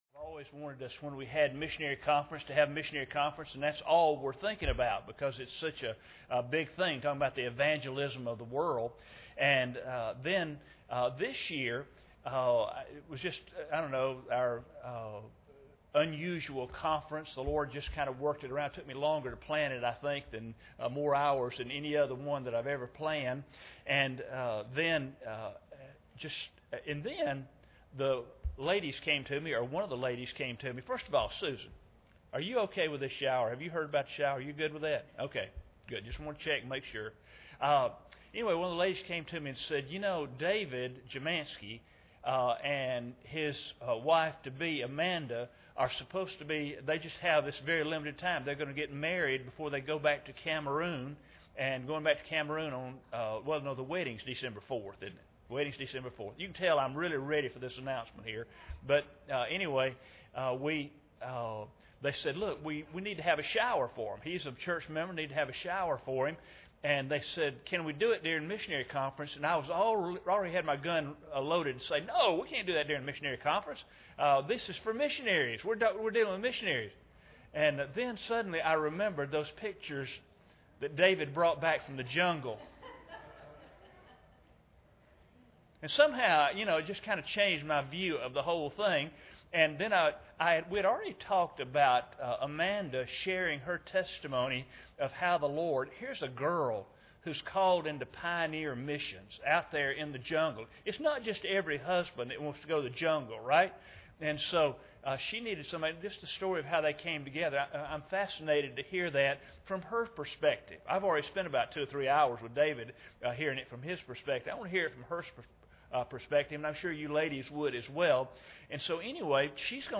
Passage: Acts 27:14-28:6 Service Type: Sunday Evening